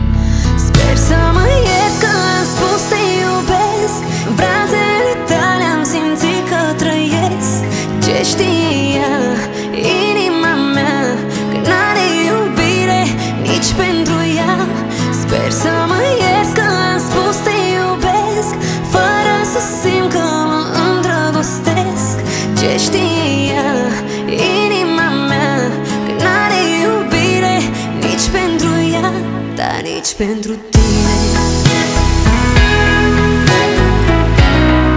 Pop
Жанр: Поп музыка